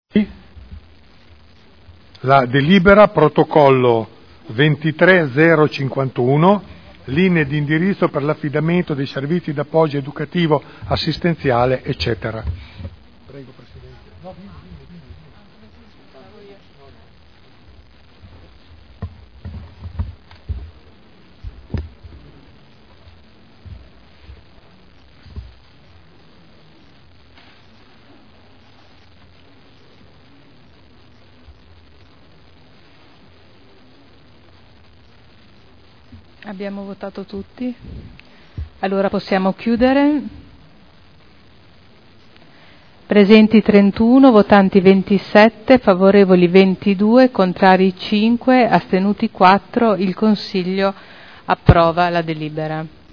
Seduta del 12/04/2012. Mette ai voti la delibera Linee di indirizzo per l’affidamento dei servizi di appoggio educativo assistenziale per gli alunni diversamente abili delle scuole di ogni ordine e grado site nel Comune di Modena (Commissione consiliare del 15 marzo e 28 marzo 2012).